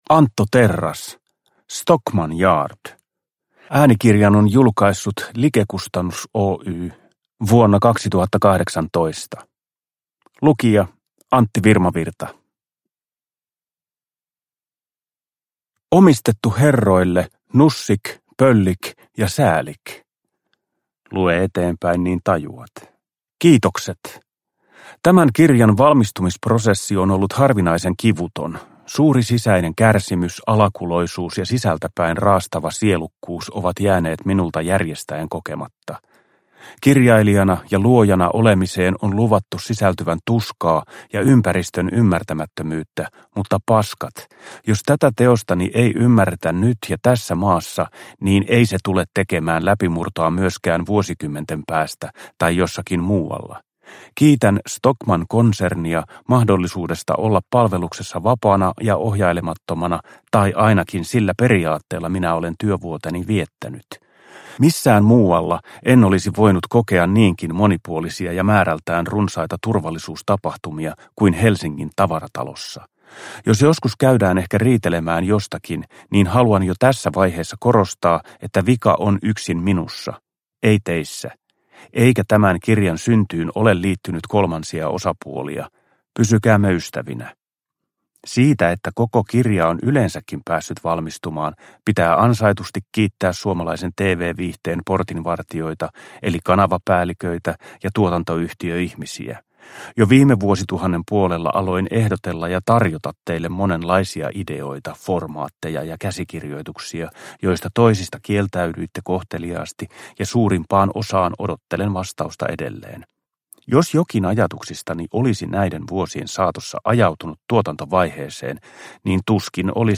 Stockmann Yard - Myymäläetsivän muistelmat – Ljudbok
Uppläsare: Antti Virmavirta